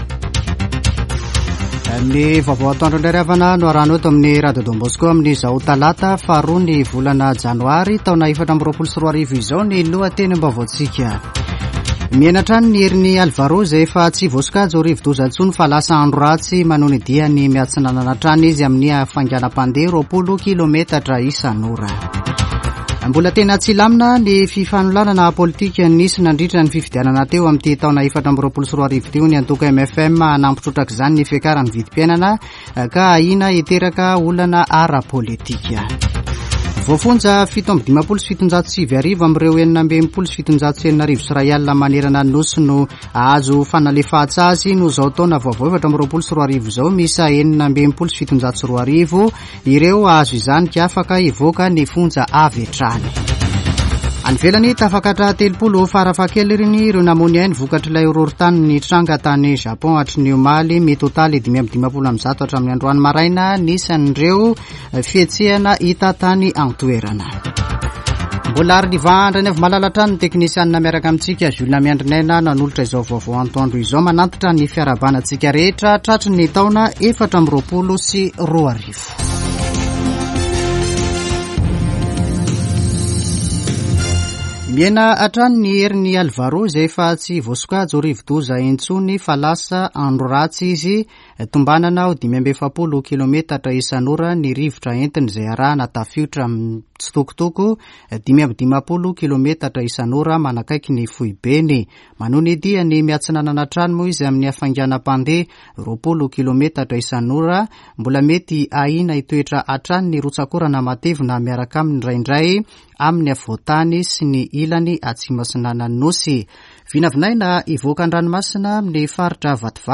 [Vaovao antoandro] Talata 2 janoary 2024